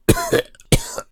Cough2.ogg